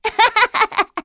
Hanus-Vox_Happy3.wav